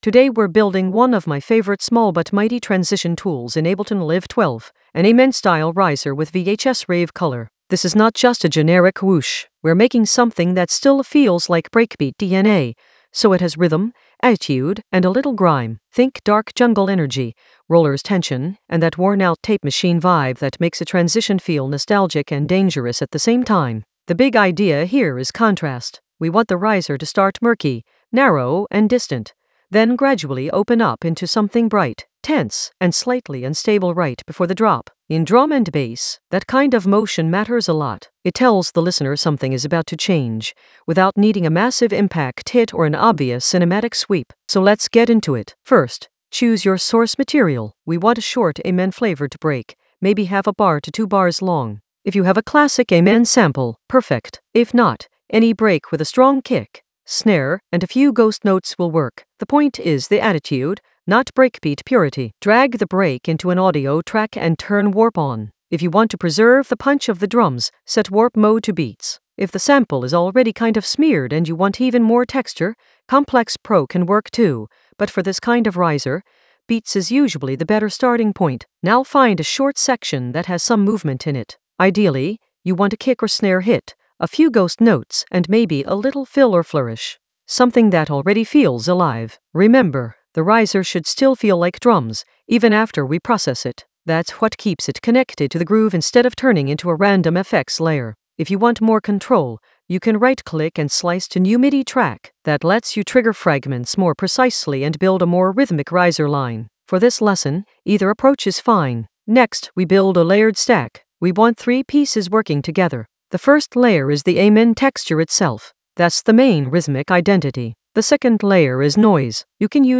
Narrated lesson audio
The voice track includes the tutorial plus extra teacher commentary.
An AI-generated intermediate Ableton lesson focused on Layer an Amen-style riser for VHS-rave color in Ableton Live 12 in the Automation area of drum and bass production.